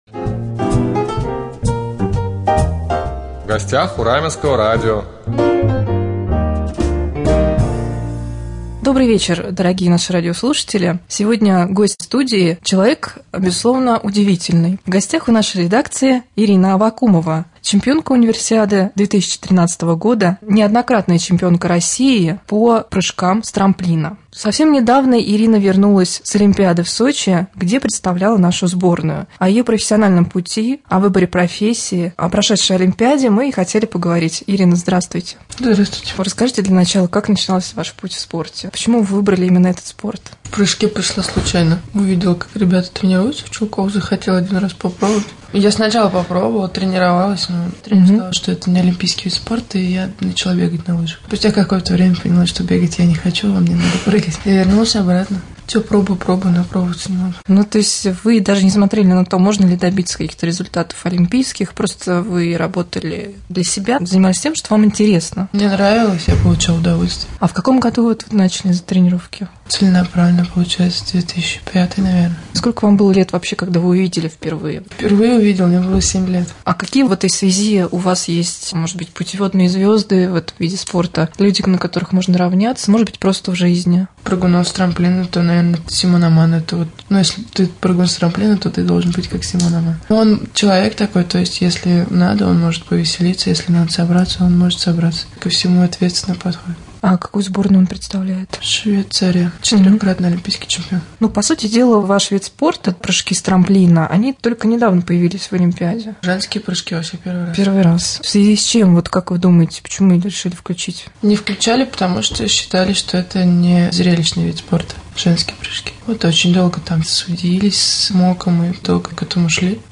Беседа с чемпионкой России по прыжкам с трамплина